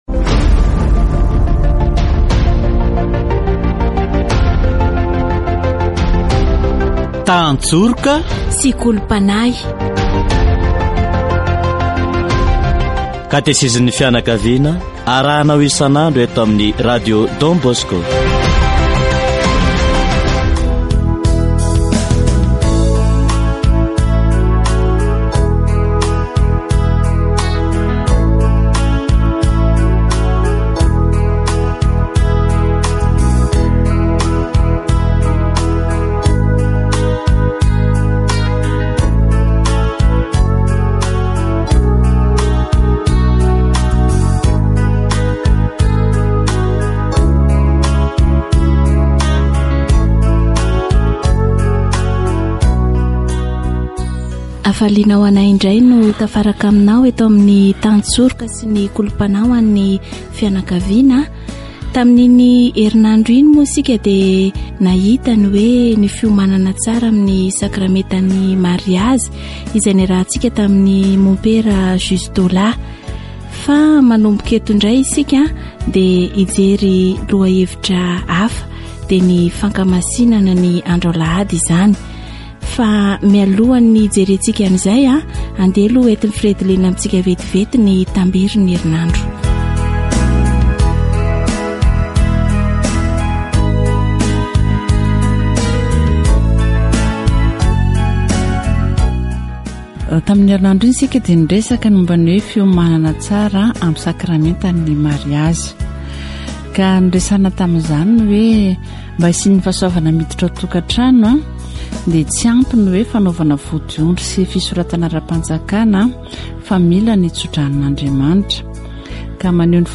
Catechesis on Sunday observance